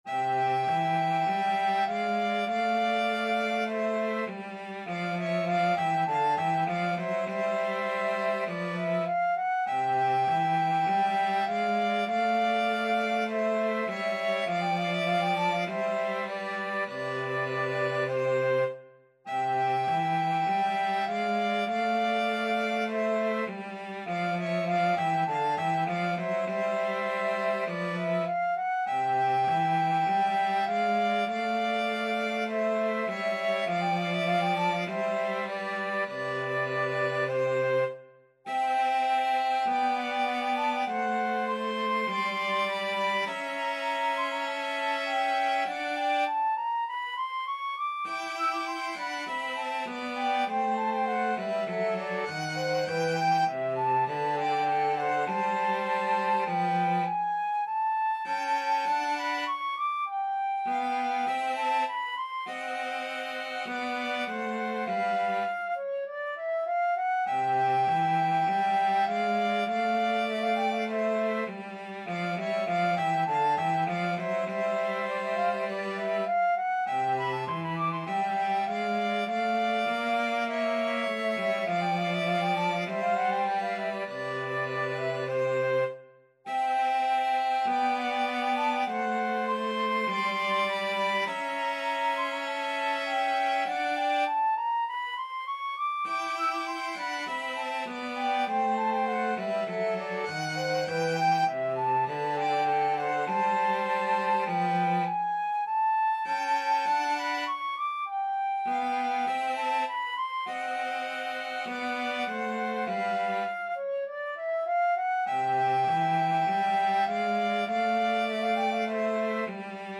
4/4 (View more 4/4 Music)
Classical (View more Classical flute-violin-cello Music)